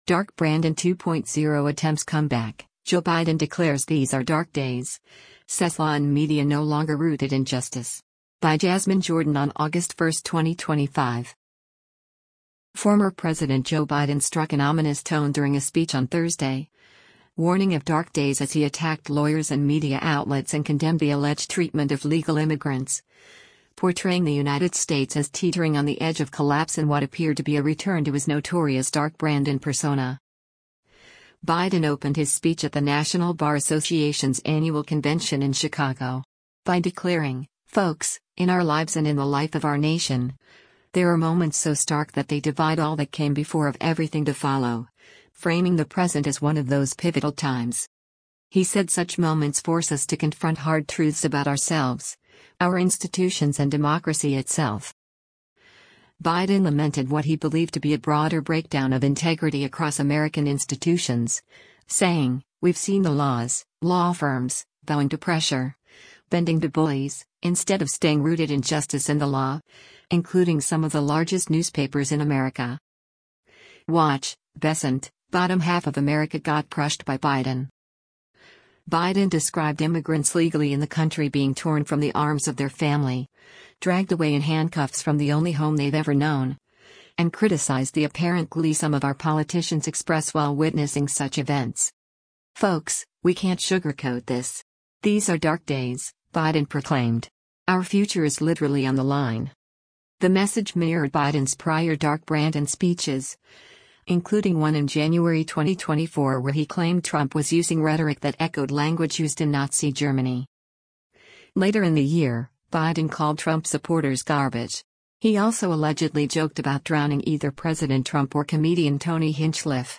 Former President Joe Biden struck an ominous tone during a speech on Thursday, warning of “dark days” as he attacked lawyers and media outlets and condemned the alleged treatment of legal immigrants, portraying the United States as teetering on the edge of collapse in what appeared to be a return to his notorious “Dark Brandon” persona.
Biden opened his speech at the National Bar Association’s annual convention in Chicago. by declaring, “Folks, in our lives and in the life of our nation, there are moments so stark that they divide all that came before of everything to follow,” framing the present as one of those pivotal times.